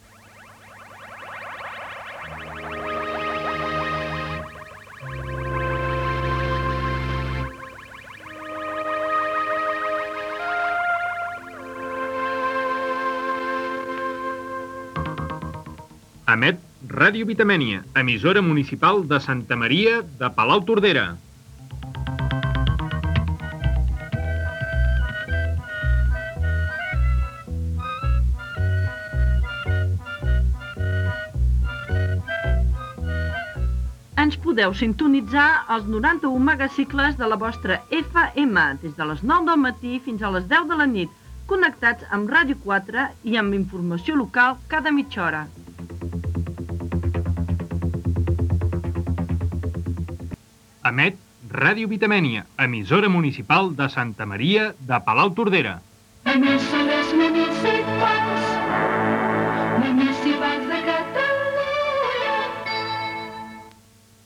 Banda FM